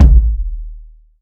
Kick (124).wav